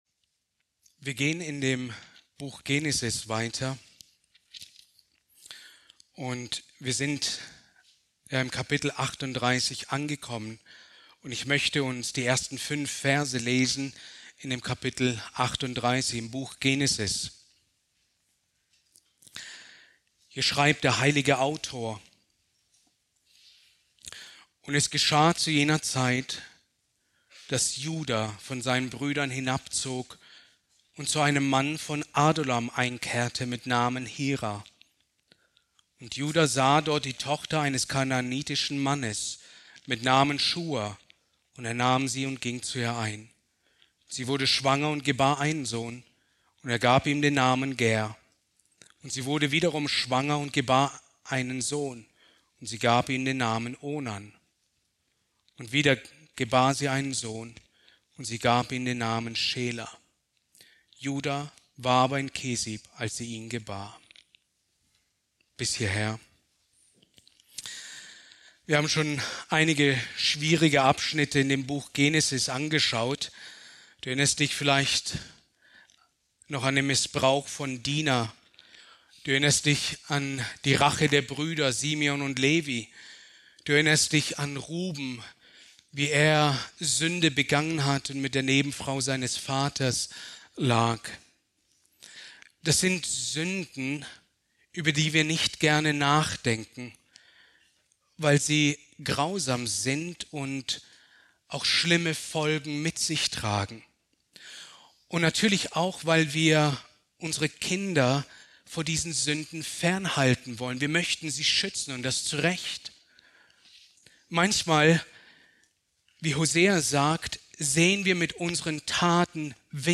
Predigt aus der Serie: "Genesis"